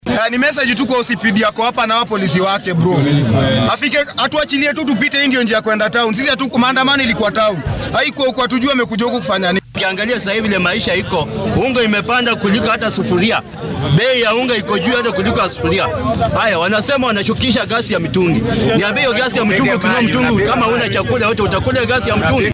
Qaar ka mid ah dhallinyaradaasi ayaa warbaahinta la hadlay.
Dhallinyarada-Embakasi.mp3